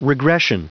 Prononciation du mot regression en anglais (fichier audio)